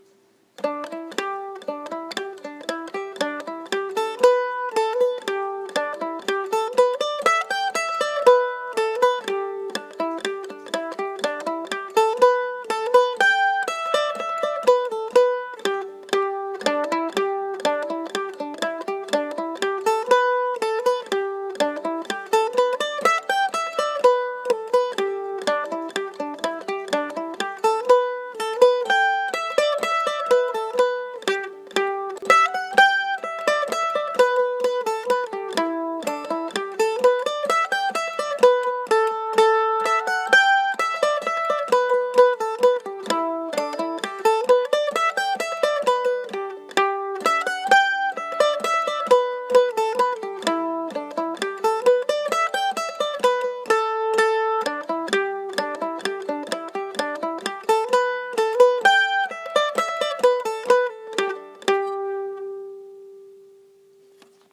So, keeping with the theme of syncopated reels, our tune for next week is Spootiskerry and the syncopation is right out of the gates, so it is easy to be ready for it.
Spootiskerry Reel (Key of G)